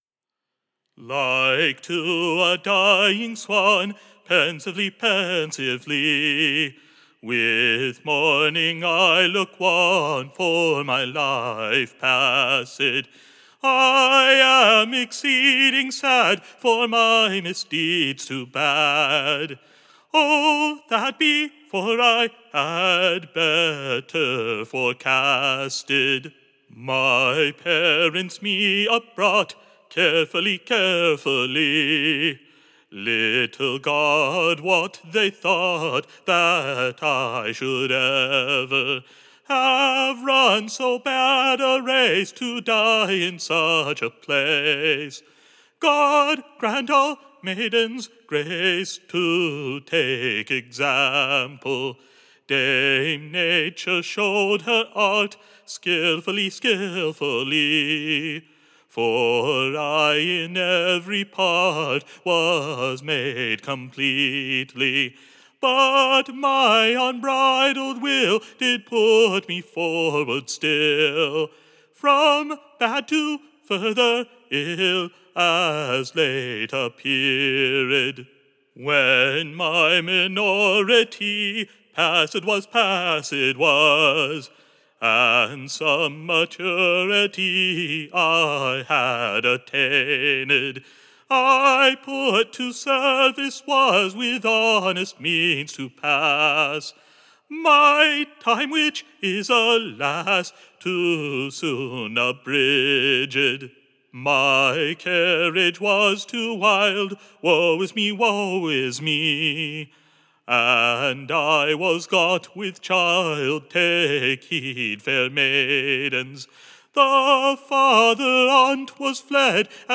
Recording Information Ballad Title No naturall Mother, but a Monster.
Tune Imprint To the tune of, Welladay.